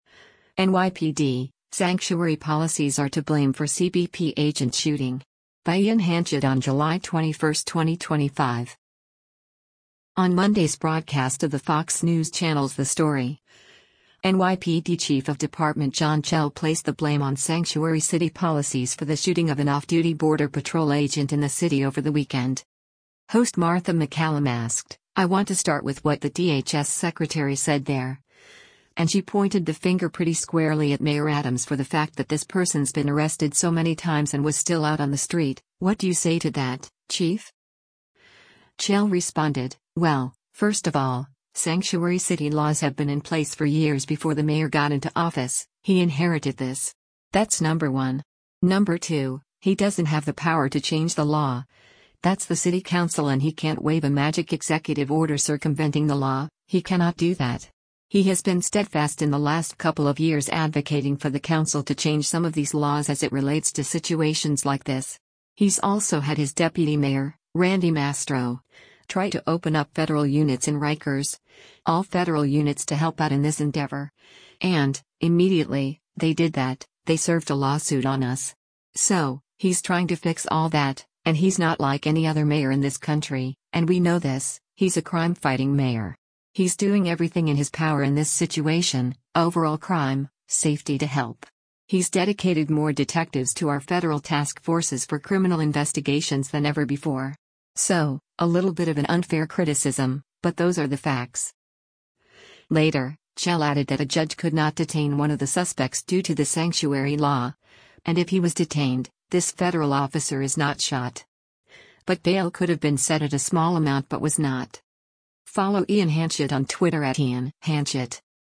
On Monday’s broadcast of the Fox News Channel’s “The Story,” NYPD Chief of Department John Chell placed the blame on sanctuary city policies for the shooting of an off-duty Border Patrol agent in the city over the weekend.